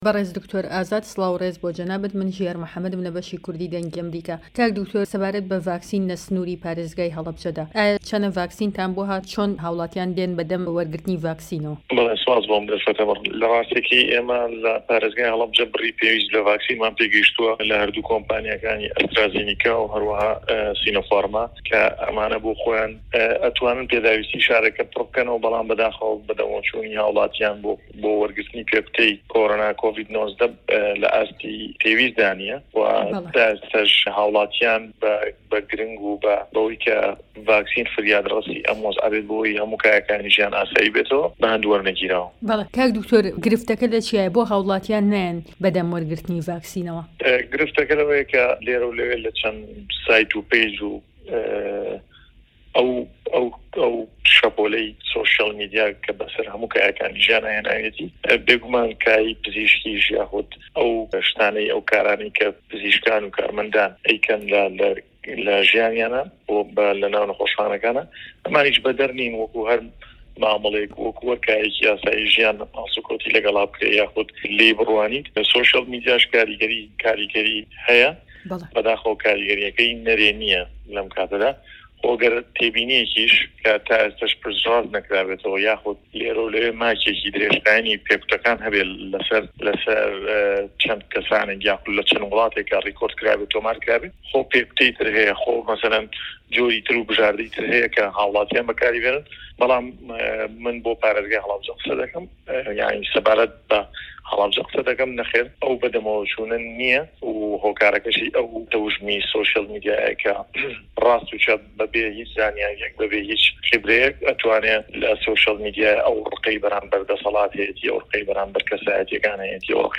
وتووێژەکەی